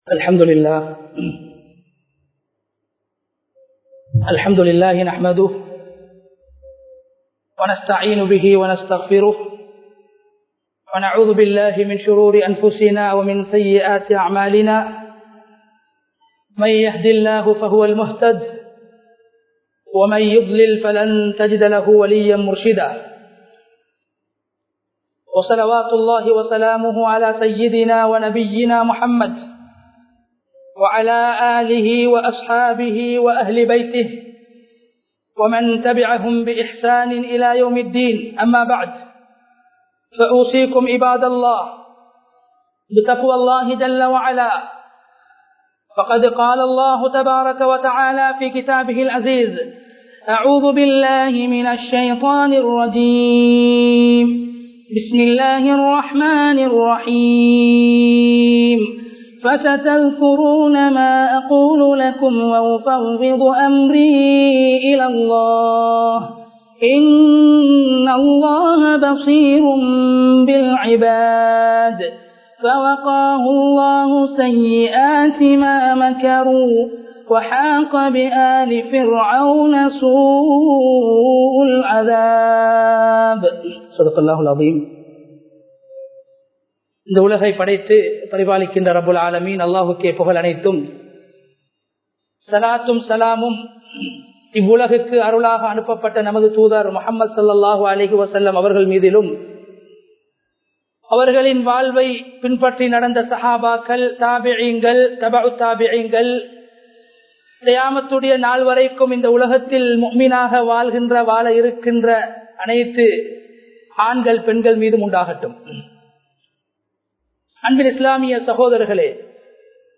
தவக்குல் | Audio Bayans | All Ceylon Muslim Youth Community | Addalaichenai